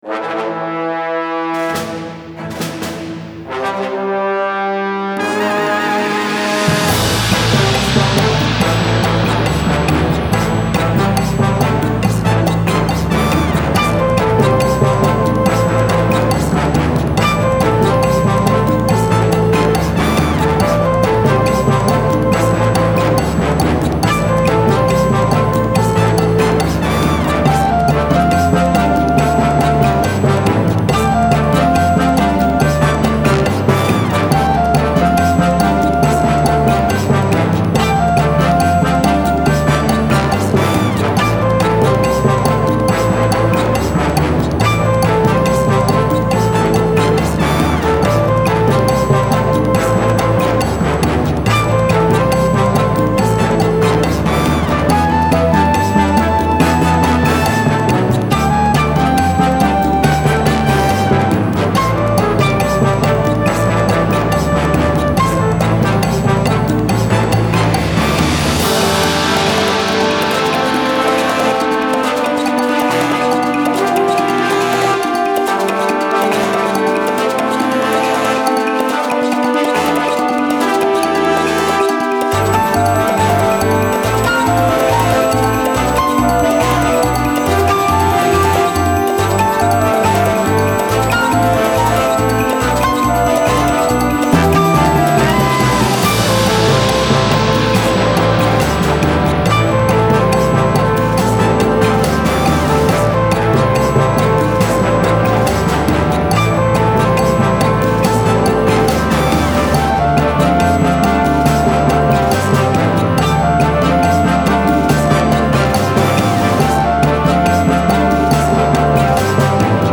Style Style Jazz, Soundtrack
Mood Mood Bouncy, Cool, Mysterious
Featured Featured Brass, Drums, Flute +2 more
BPM BPM 140